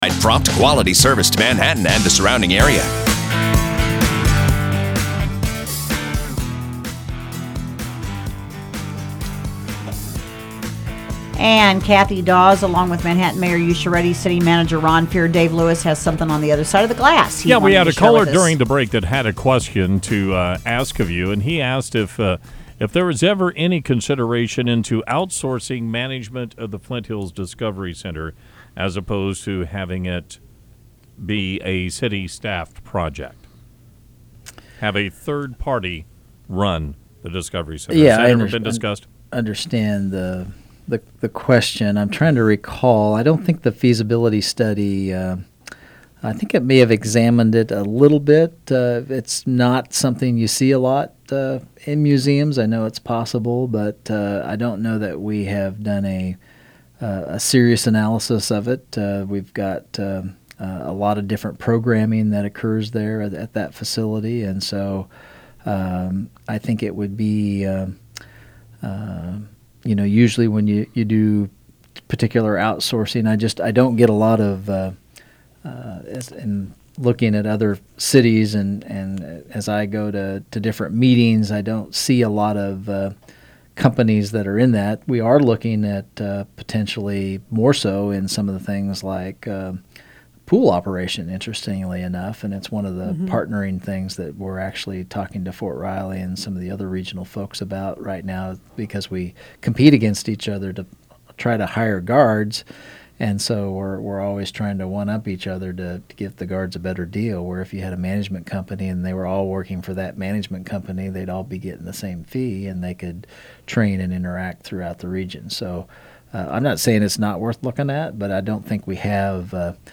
Today’s guests on In Focus were Manhattan Mayor Usha Reddi and City Manager Ron Fehr.